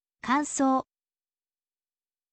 kansou